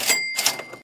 kaching.wav